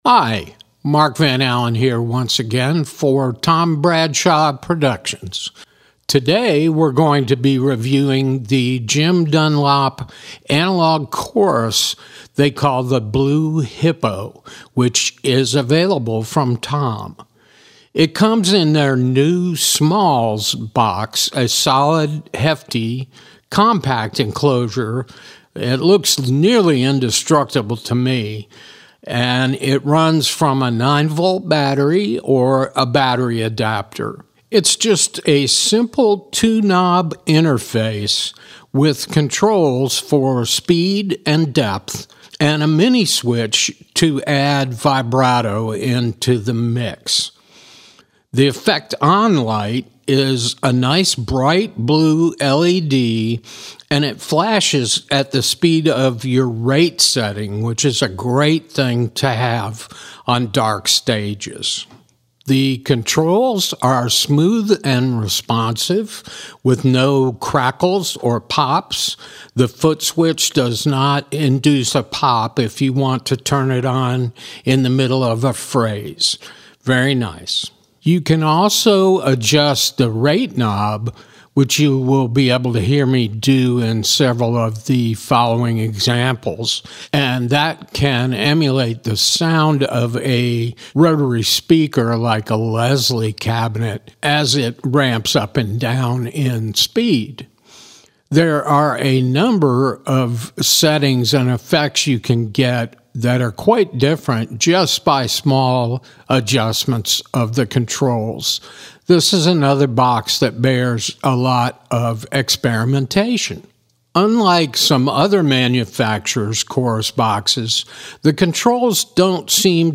With the Blue Hippo, your steel, electric guitar or about any amplified instrument will project the sound of an organ’s rotating speaker.
The switchable vibrato provides a stand-out sound-benefit.
blue_hippo_demo.mp3